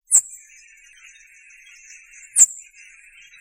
Martin-pêcheur